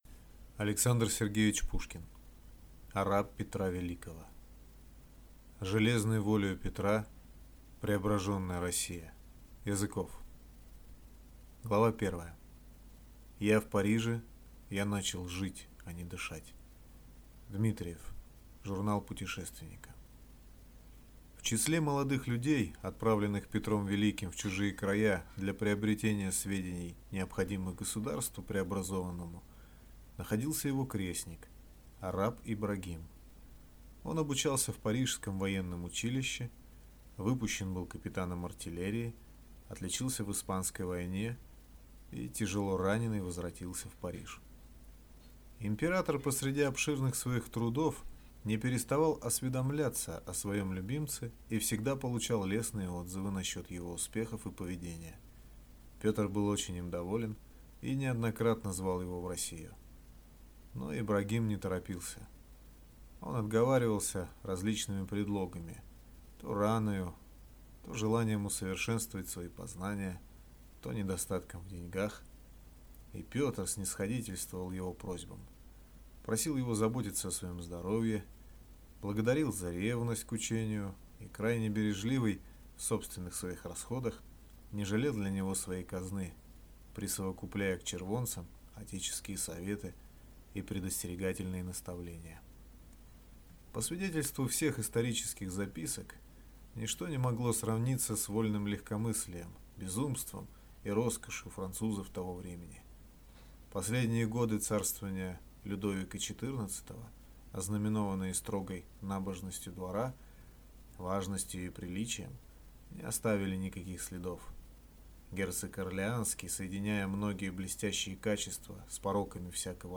Арап Петра Великого - аудио роман Пушкина - слушать онлайн